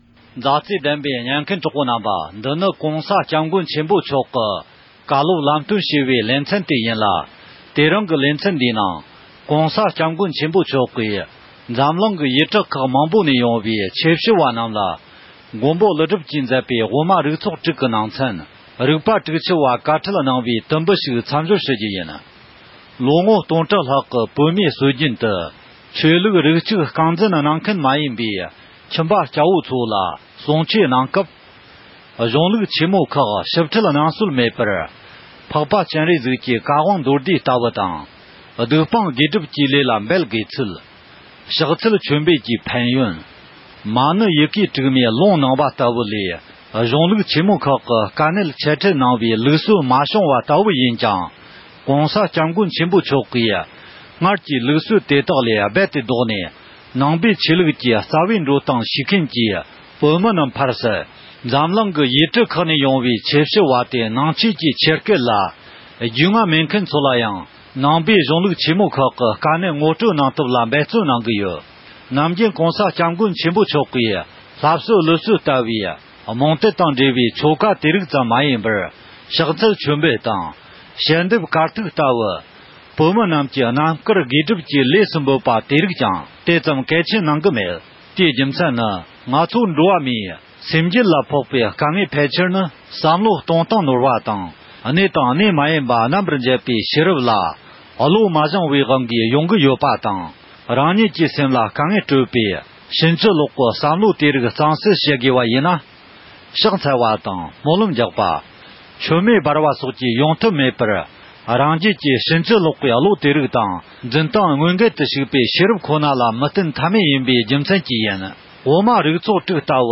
མགོན་པོ་ཀླུ་སྒྲུབ་ཀྱིས་མཛད་པའི་དབུ་མ་རིག་ཚོགས་དྲུག་གི་ནང་ཚན། རིག་པ་དྲུག་བཅུ་པའི་བཀའ་ཁྲི་གནང་བའི་དུམ་བུ་ཞིག་སྙན་སྒྲོན་ཞུ་རྒྱུ་ཡིན།།